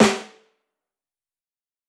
SNARE_RINGO.wav